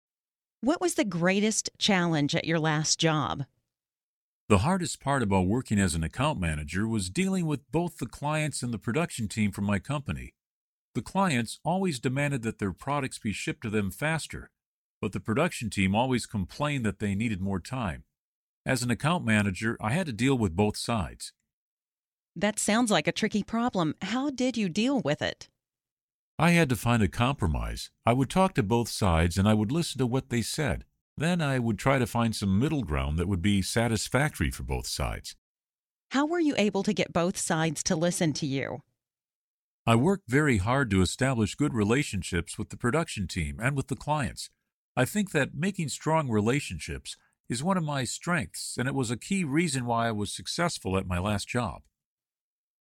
Learn different ways to answer the interview question 'What was the greatest challenge at your last job?', listen to an example conversation, and study example sentences.